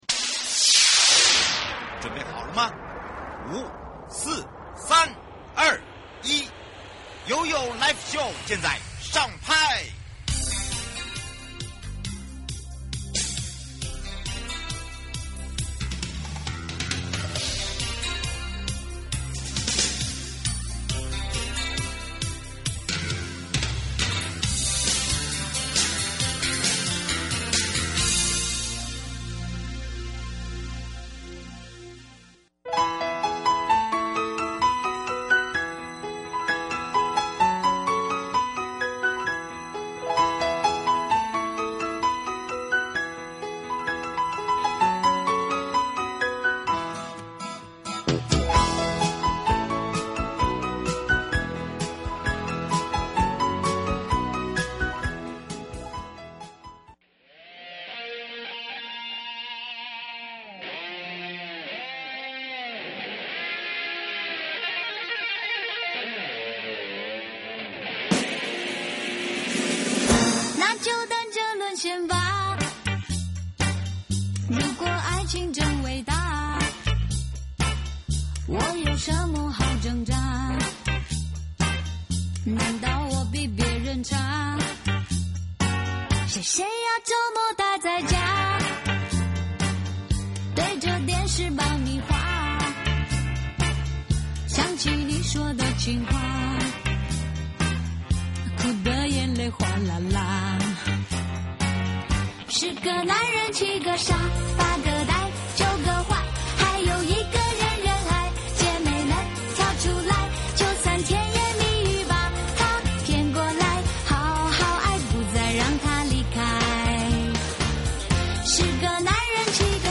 受訪者： 1.大鵬灣管理處許主龍處長